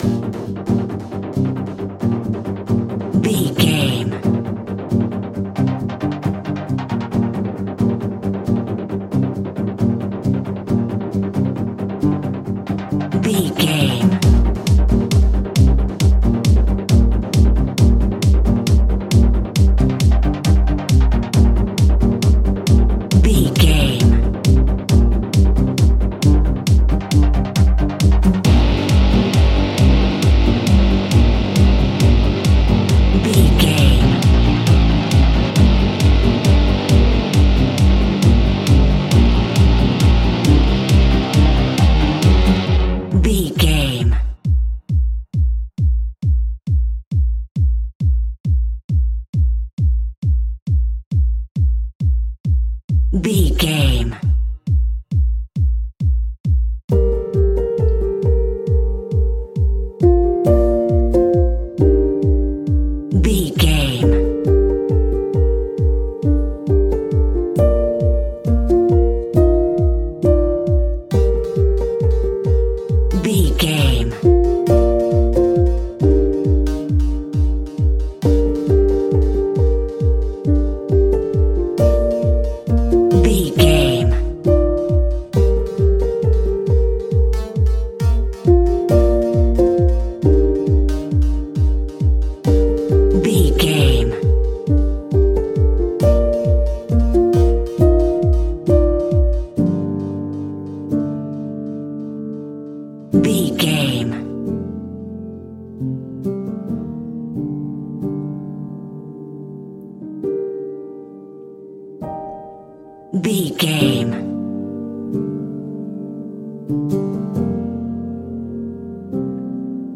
Aeolian/Minor
strings
percussion
synthesiser
brass
cello